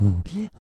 声乐音节 hoo hee " mbhoohee2 022
描述：声乐音节，通过用SM58录制有点节奏的无意义的谈话，然后对音频进行自动分割而形成的。
标签： 气息 人性化 音素 音节 声音
声道立体声